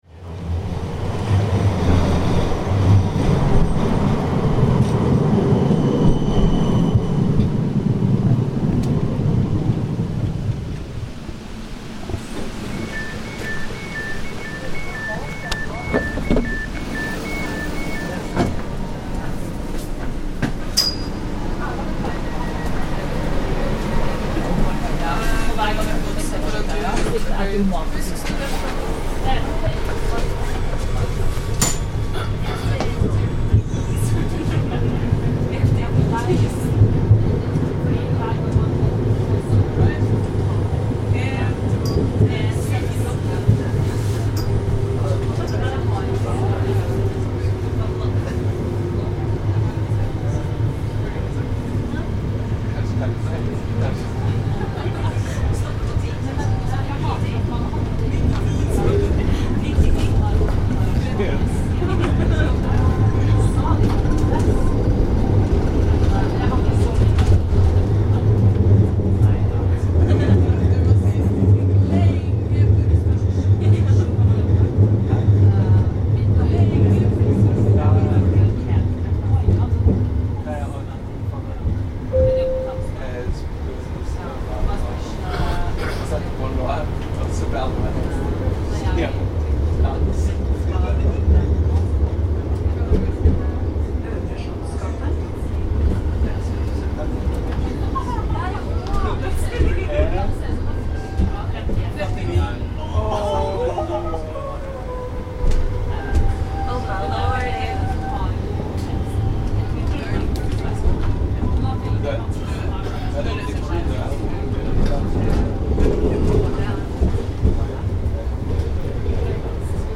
Aboard the tram to Jernbanetorget
The tram ride in Oslo, Norway from Kontraskjaeret to Jernbanetorget outside the main station, with beeps, doors opening and closing, track sounds and announcements.